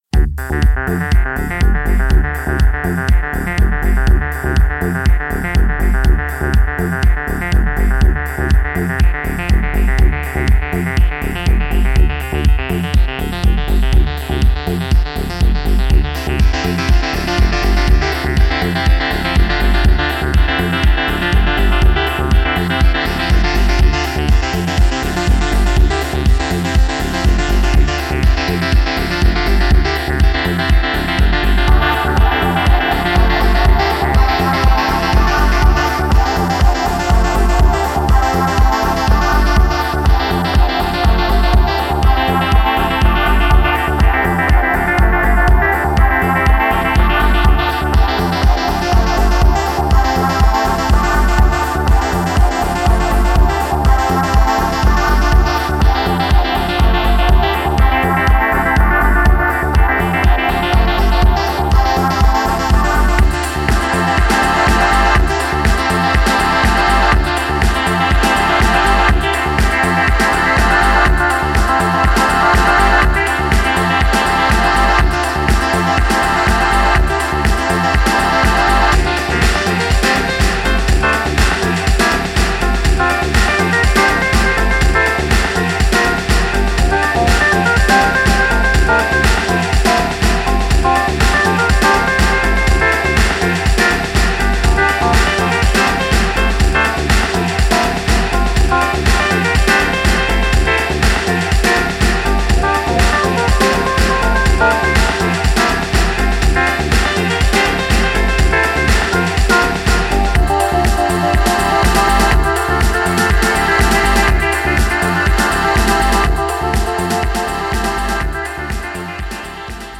House Techno